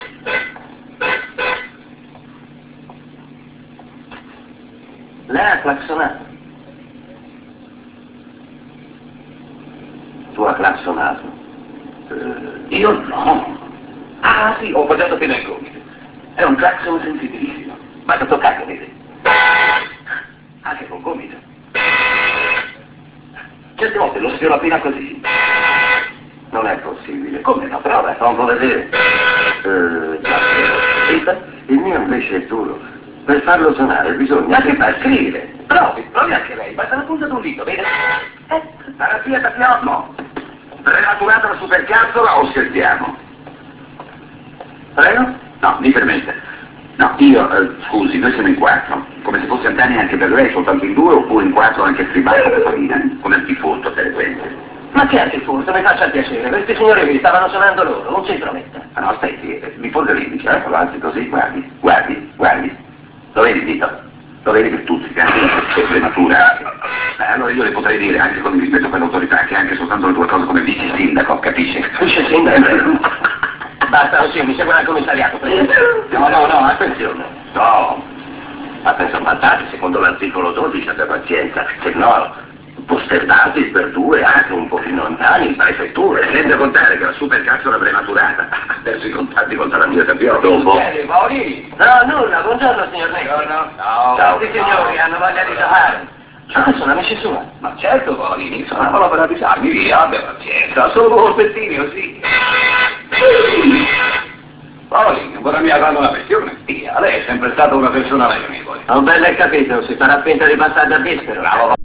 Also you can download a small file in *.ra format to hear Ugo Tognazzi's voice.
utvoice.ra